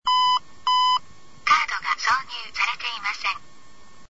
DIU-9000 音声案内　（MP3録音ファイル）